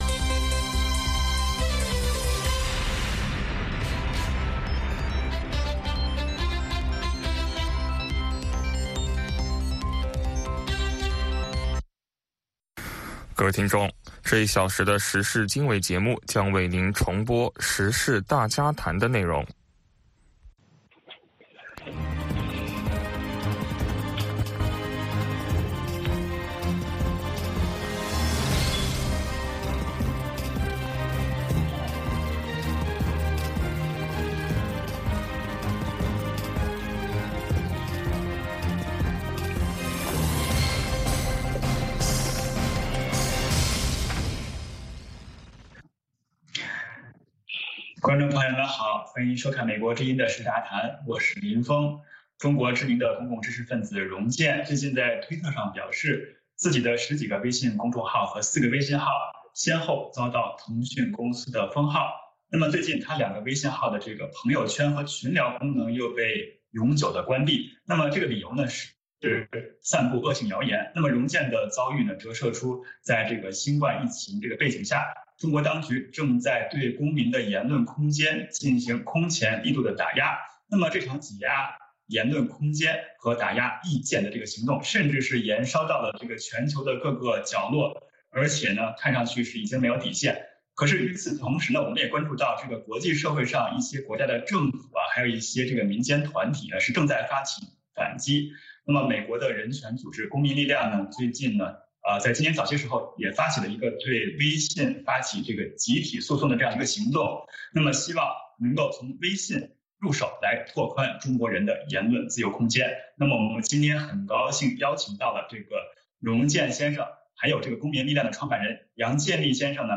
美国之音中文广播于北京时间每天晚上7-8点播出《时事经纬》节目。《时事经纬》重点报道美国、世界和中国、香港、台湾的新闻大事，内容包括美国之音驻世界各地记者的报道，其中有中文部记者和特约记者的采访报道，背景报道、世界报章杂志文章介绍以及新闻评论等等。